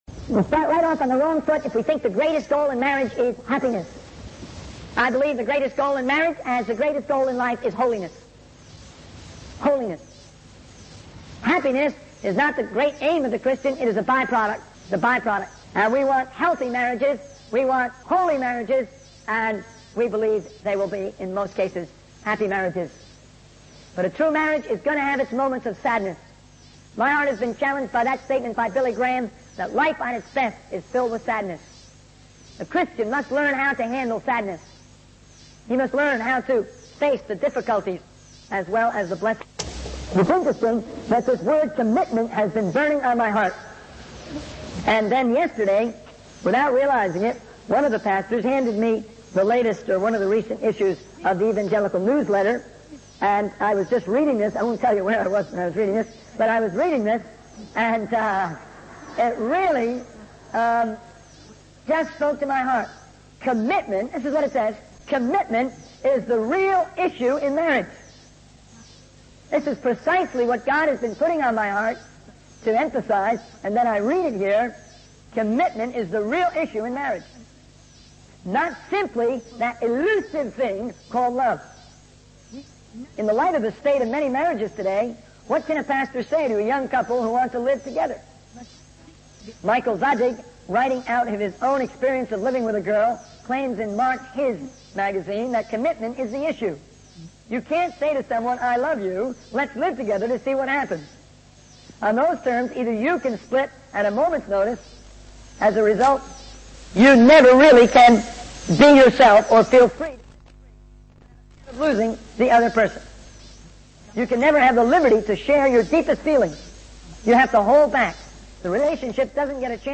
In this sermon, the speaker discusses the importance of communication and learning in marriage.